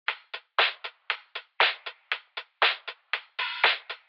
DSC_EID_118_drum_loop_linn_like_lofi